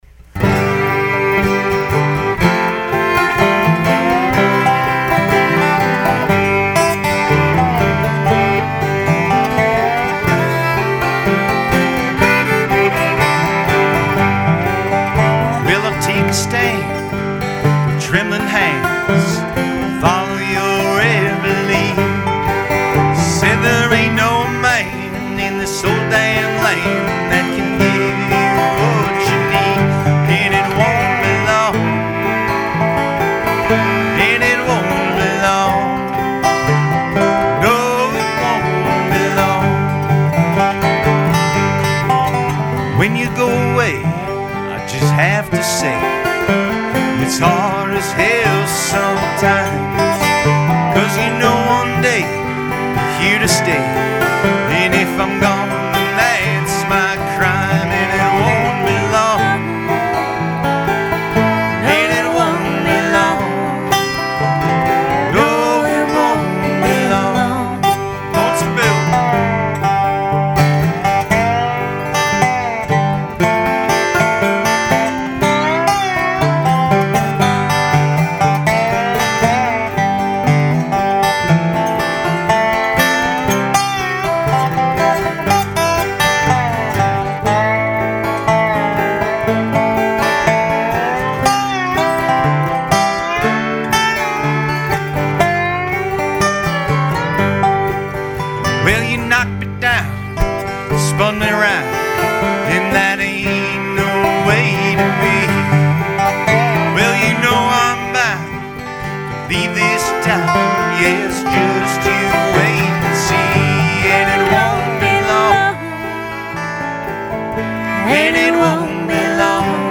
live in-studio performance
Dobro
guitar and vocals
viola, fiddle, banjo and vocals